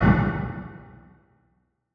MenuClick2.wav